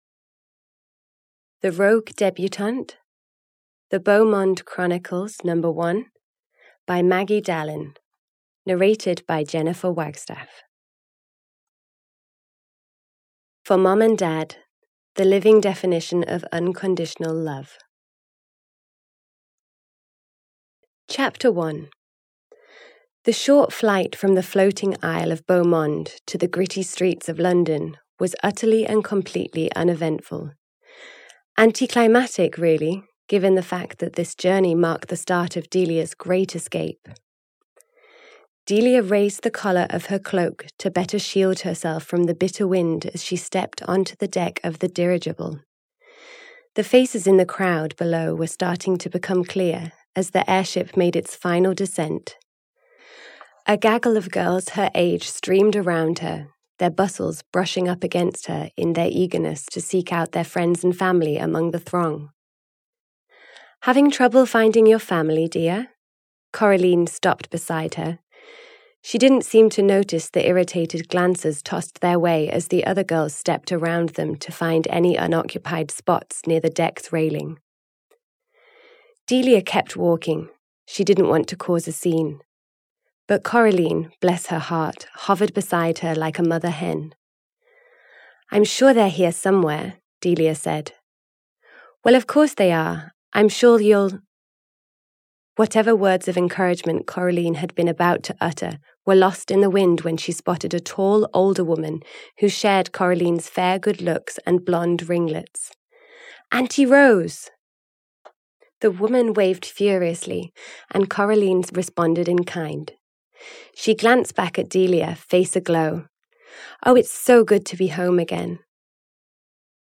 The Rogue Debutante (EN) audiokniha
Ukázka z knihy